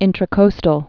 (ĭntrə-kōstəl)